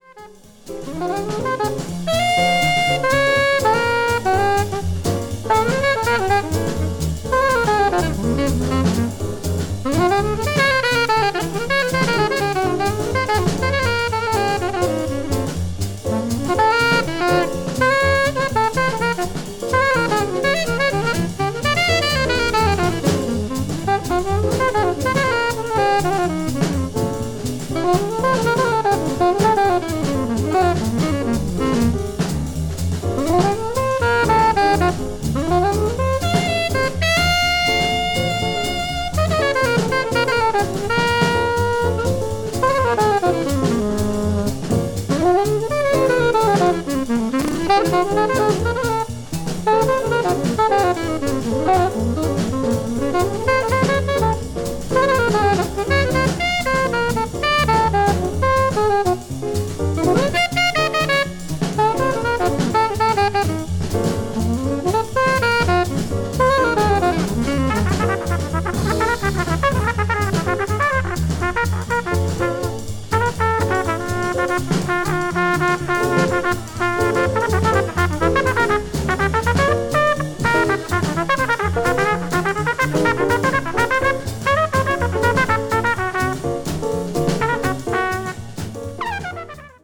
blues jazz   hard bop   modern jazz